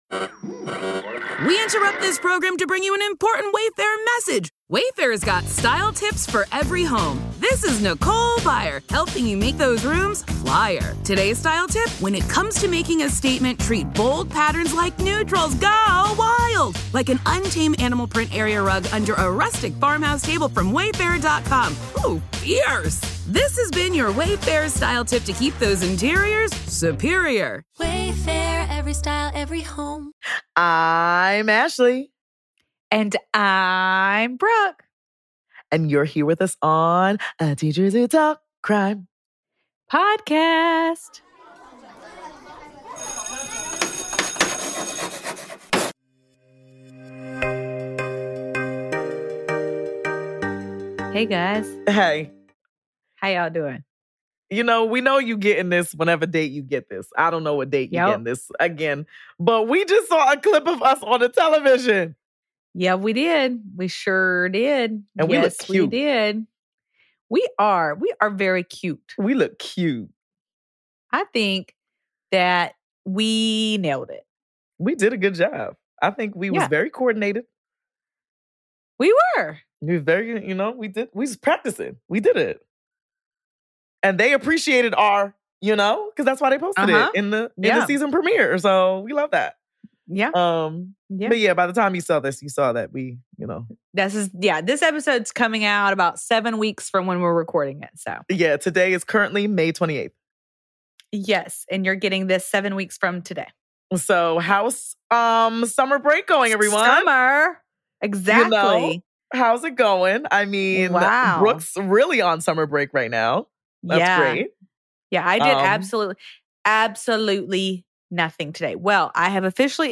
Today’s crime starts at 18:48, and yes we know that we chatted, but sometimes we just get so into our conversations that we get carried away.